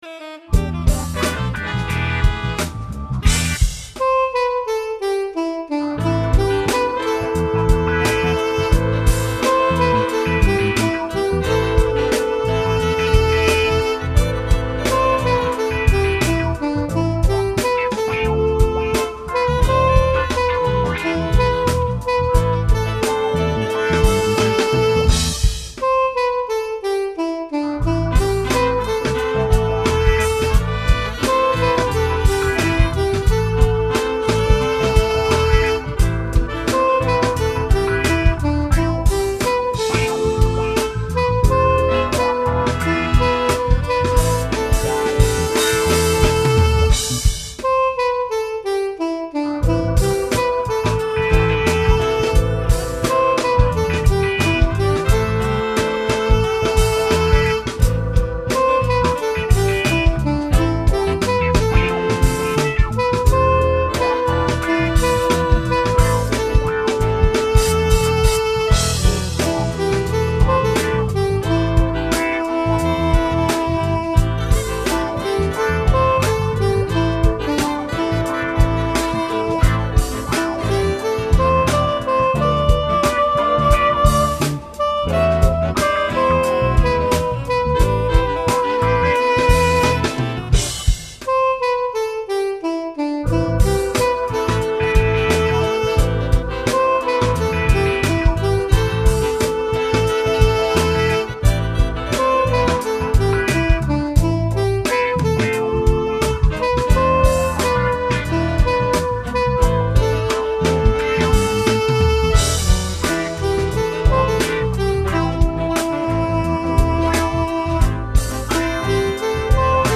funky gathering hymn
It has a bit of call and response for further participation.